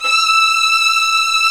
Index of /90_sSampleCDs/Roland LCDP13 String Sections/STR_Violins V/STR_Vls8 Agitato